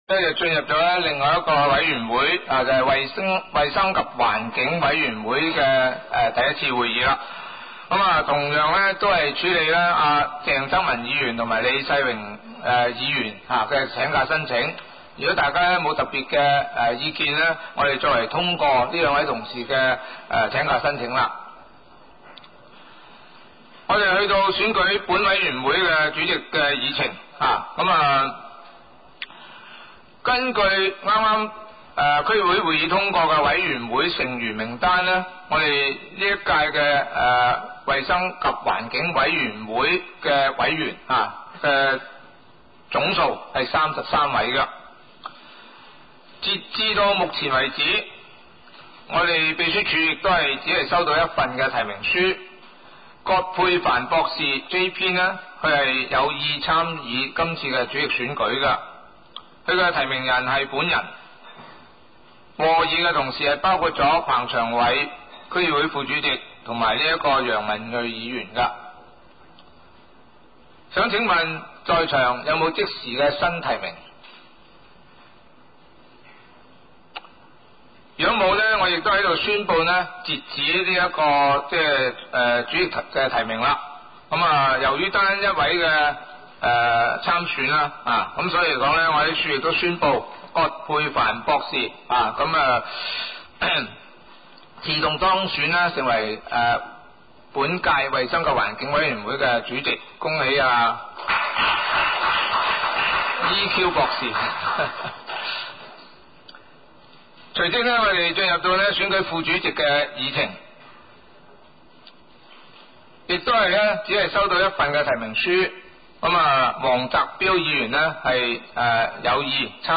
委员会会议的录音记录
地点: 沙田区议会会议室